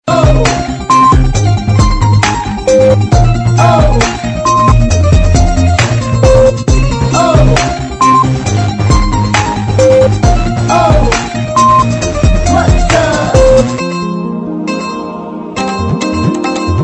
Message Tones